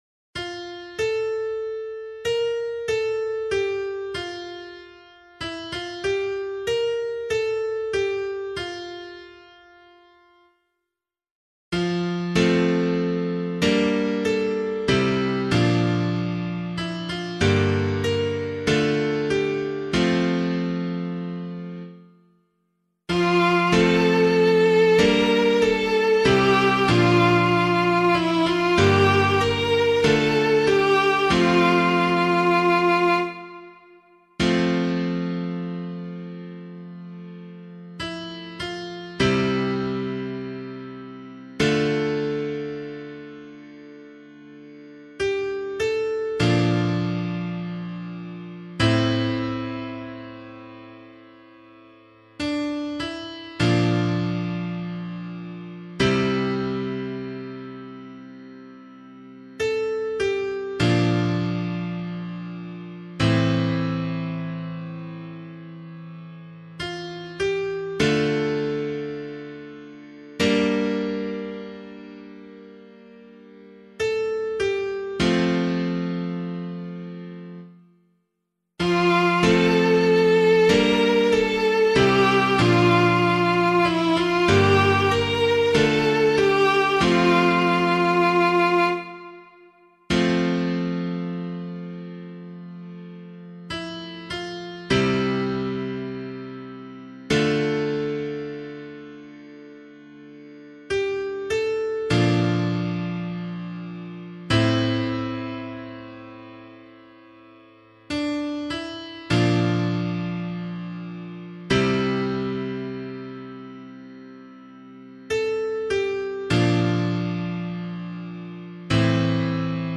026 Easter 4 Psalm A [LiturgyShare 6 - Oz] - piano.mp3